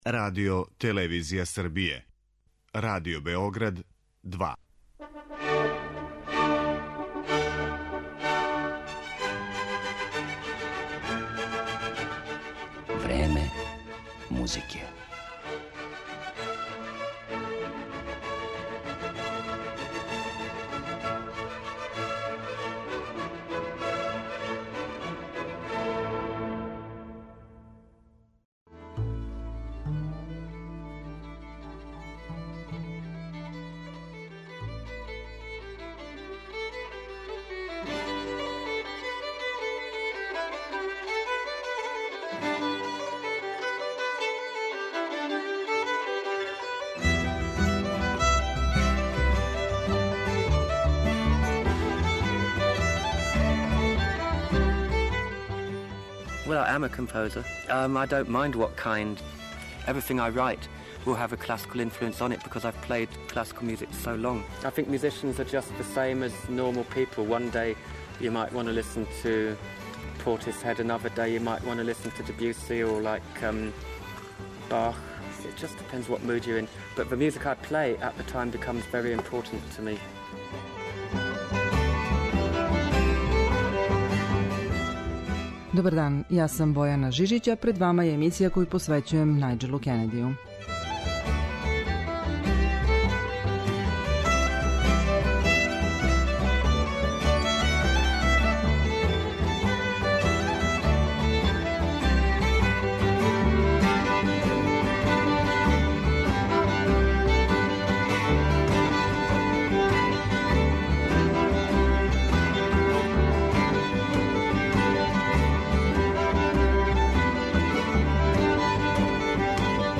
Поред врхунских интерпретација музике разноврсних стилова и жанрова, увек обележених изразитим личним печатом, моћи ћете да чујете и интервју снимљен са овим славним музичарем приликом његовог првог гостовања у Београду.